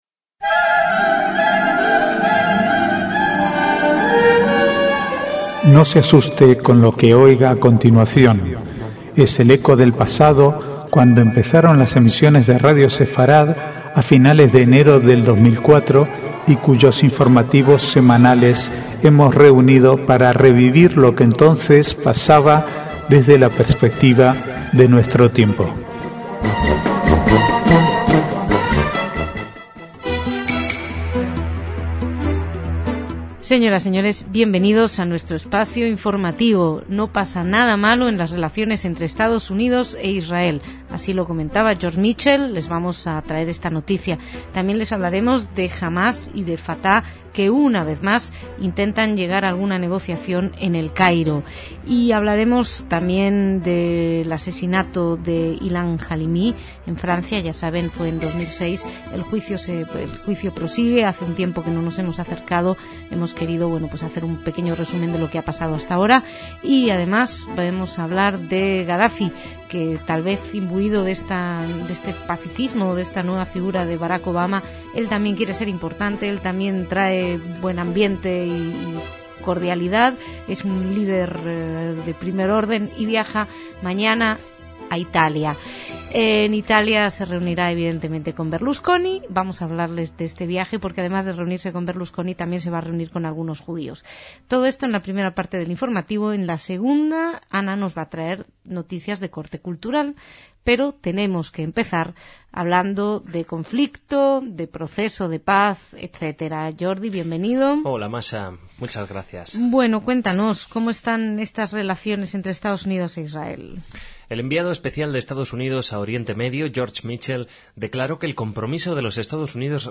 Archivo de noticias del 10 al 12/6/2009